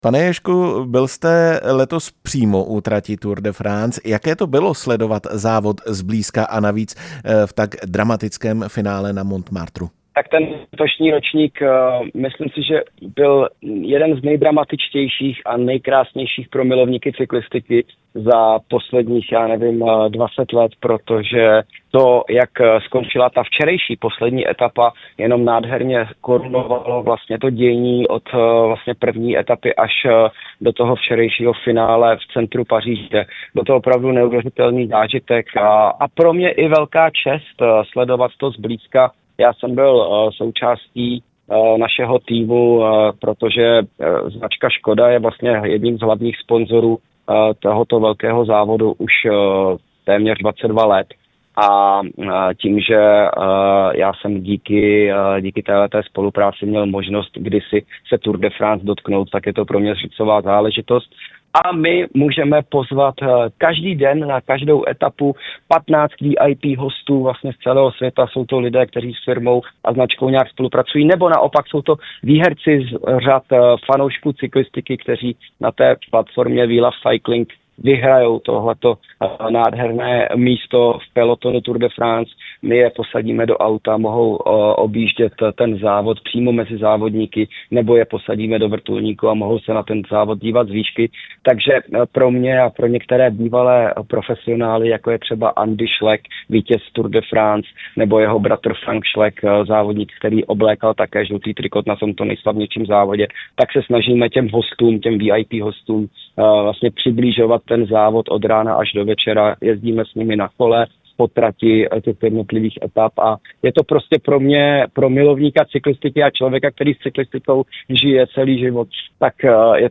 Jak se mu letošní ročník líbil? A jak se dívá na roli parasportovců v profesionální cyklistice? Ve vysílání Rádia Prostor jsme se ptali právě jeho.
Rozhovor s paralympijským vítězem Jiřím Ježkem